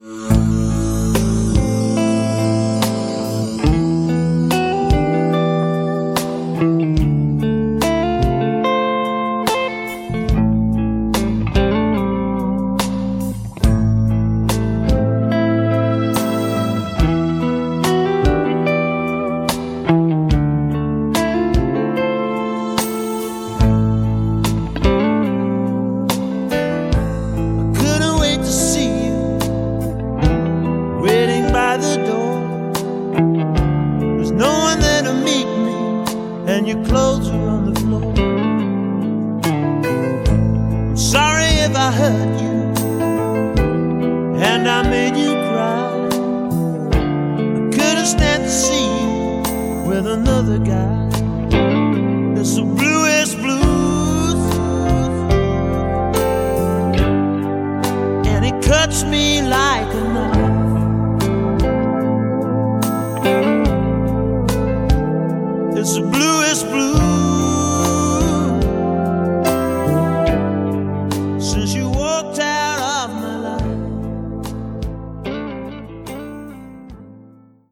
• Качество: 256, Stereo
лирические